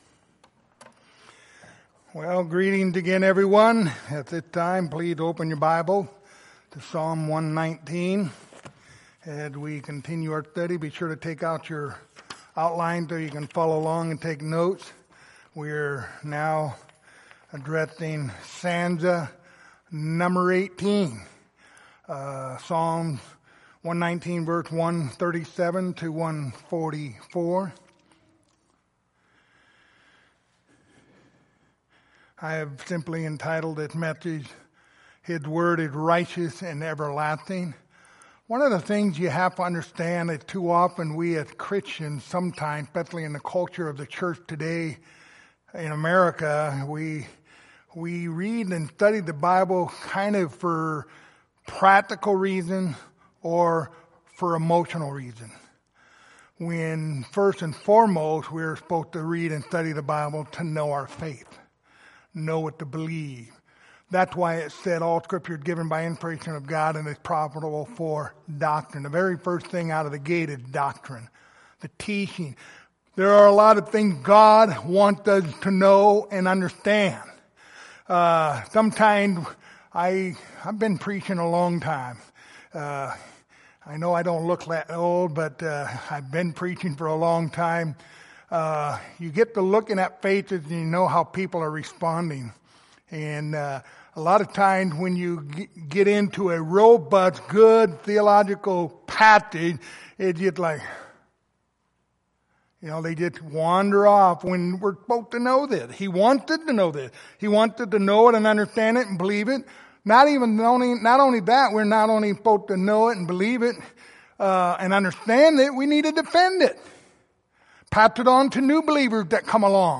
Passage: Psalm 119:137-144 Service Type: Sunday Morning